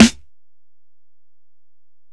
Snare (46).wav